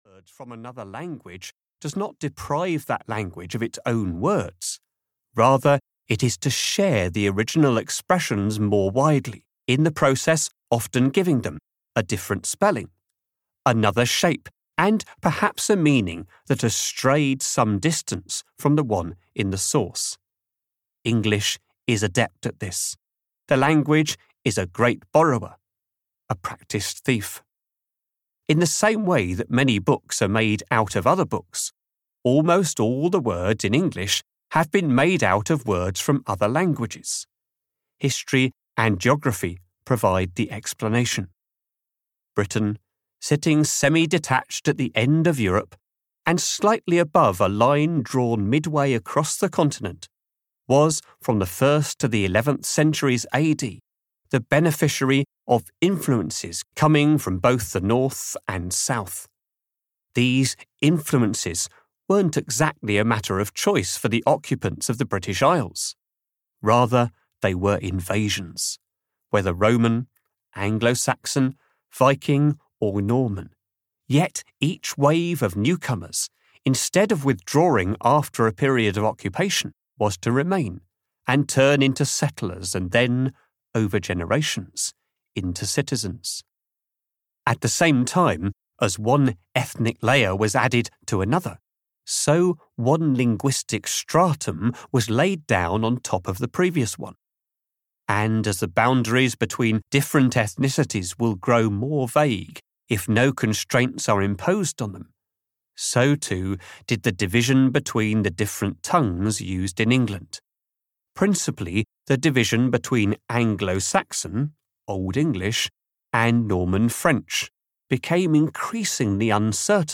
Audio knihaMay We Borrow Your Language?: How English Steals Words from All Over the World (EN)
Ukázka z knihy